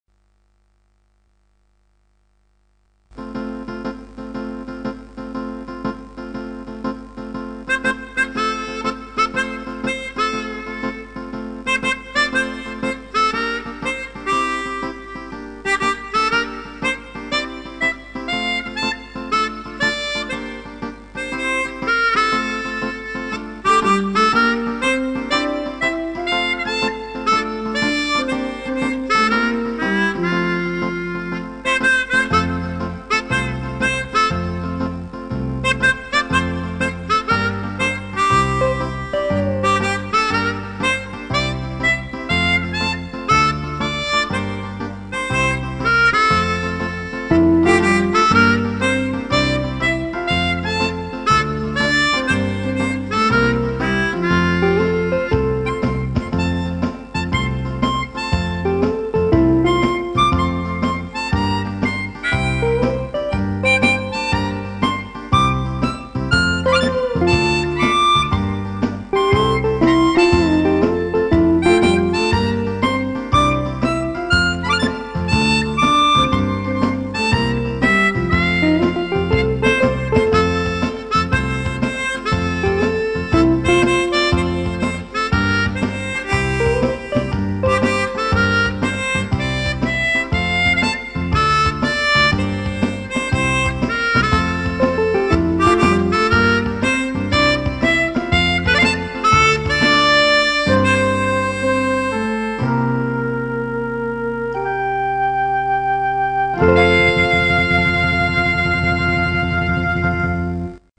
Un morceau qui donne la pêche !!!!! :102